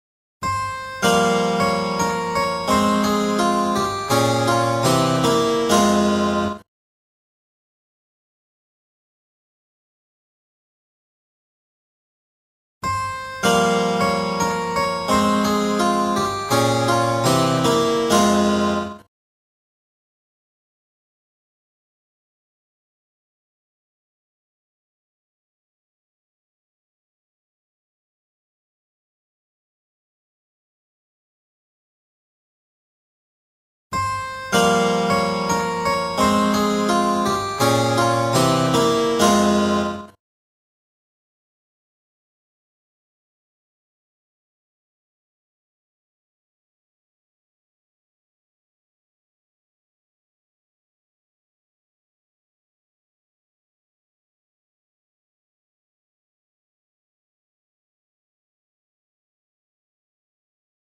Du får höra varje exempel tre gånger efter varandra med allt längre tid emellan.